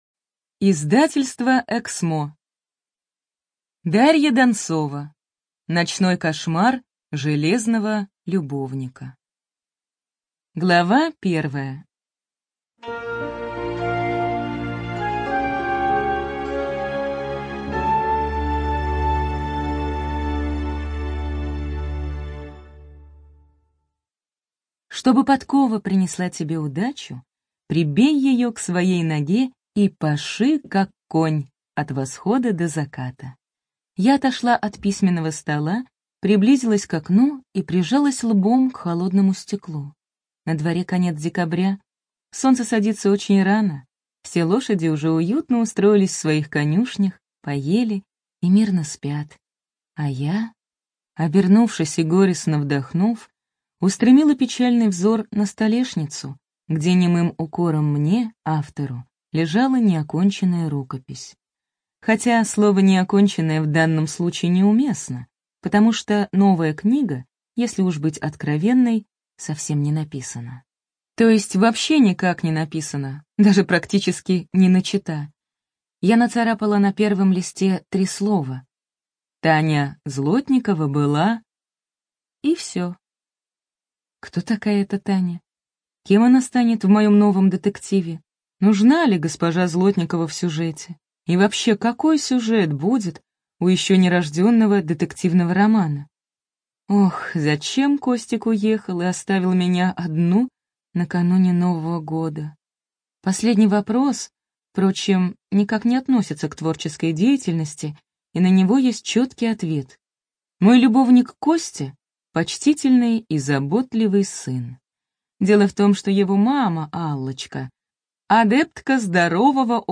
ЖанрДетективы и триллеры
Студия звукозаписиЭКСМО